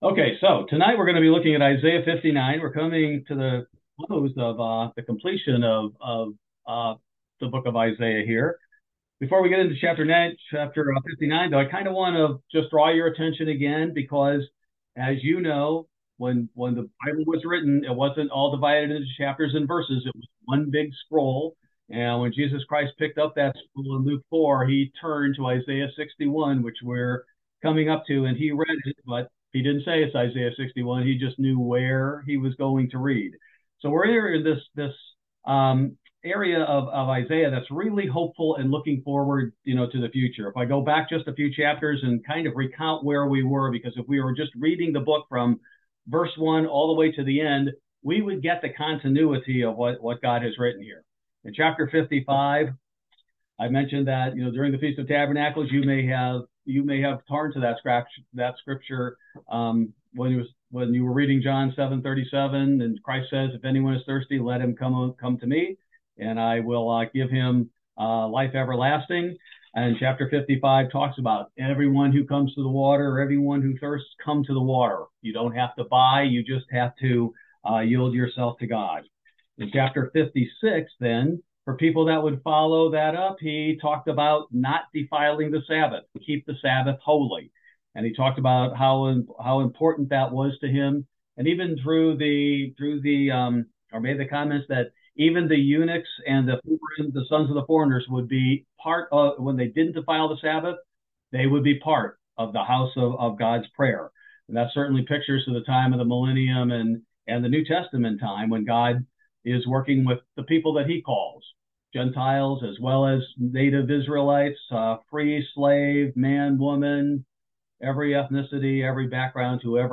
Bible Study: November 1, 2023